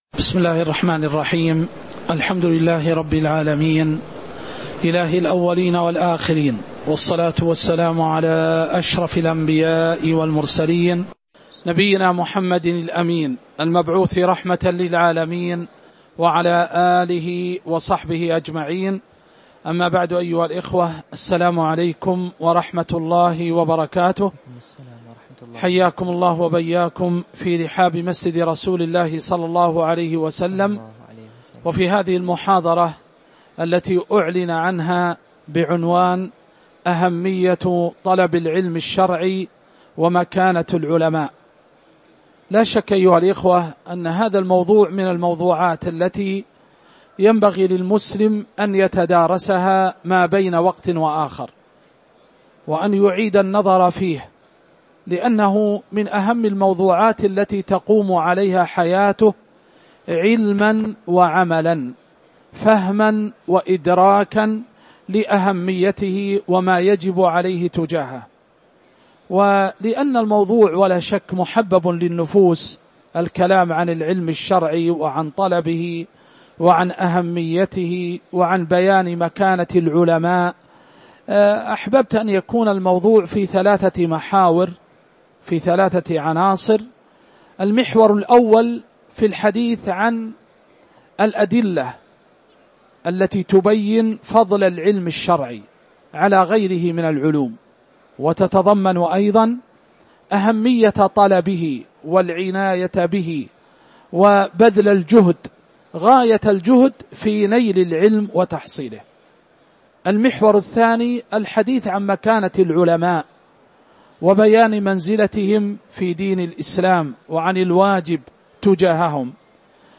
تاريخ النشر ١٨ رمضان ١٤٤٥ هـ المكان: المسجد النبوي الشيخ